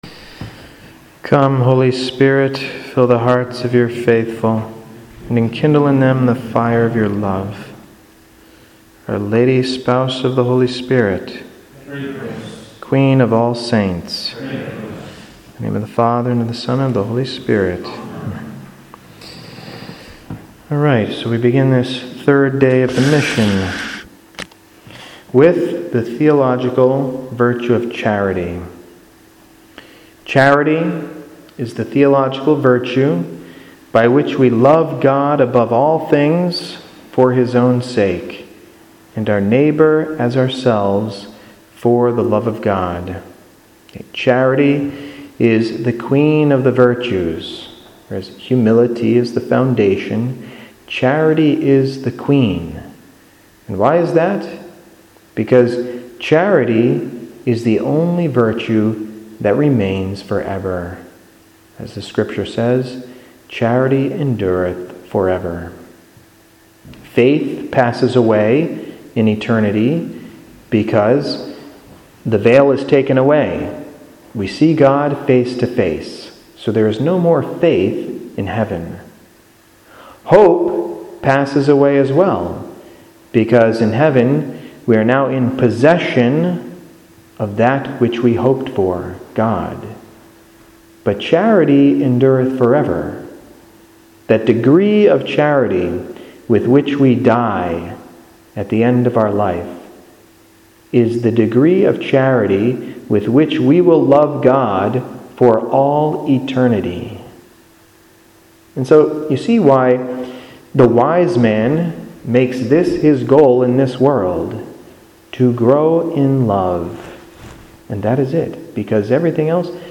In this third talk for the Mission on Heroic Virtue in England